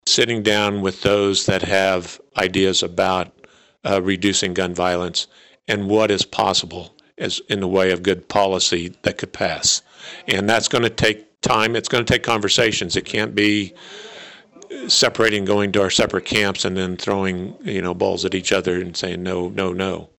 The Emporia Area Chamber of Commerce Government Matters Committee and League of Women Voters’ final legislative dialogue of the year drew a significant crowd to the Trusler Business Center Tuesday night.